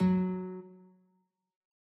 guitar.ogg